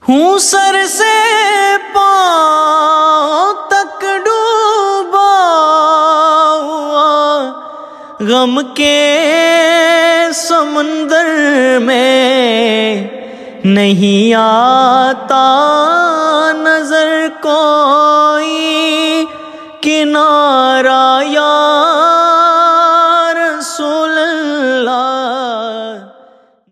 Category: Naat Ringtones